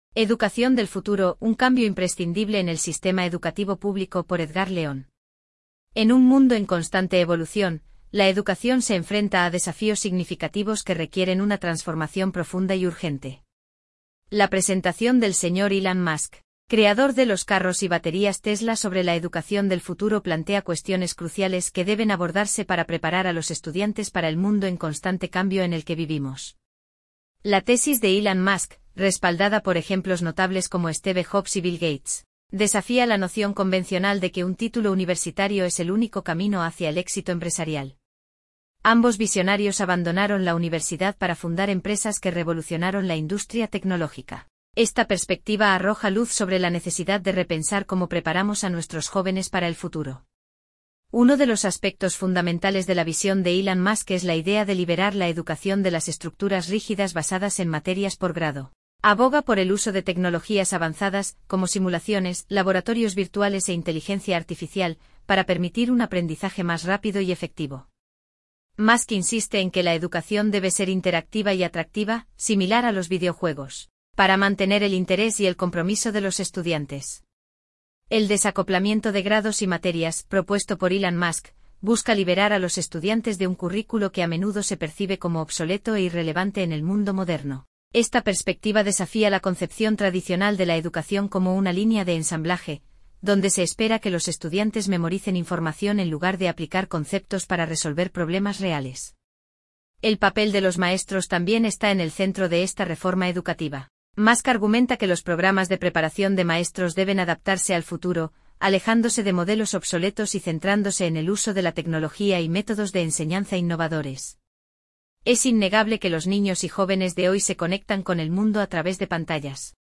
Este audio fue preparado por la inteligencia artificial y habla sobre la educacion del futuro.